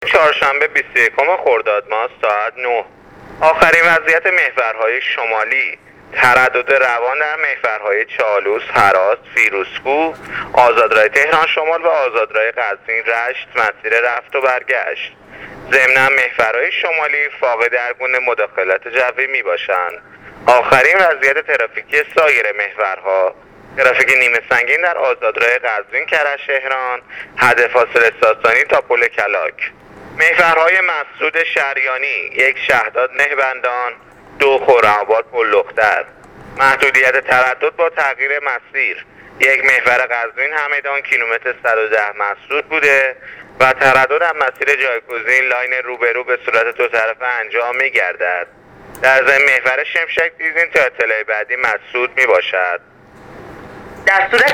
گزارش رادیو اینترنتی از وضعیت ترافیکی جاده‌ها تا ساعت ۹ بیست و یکم خردادماه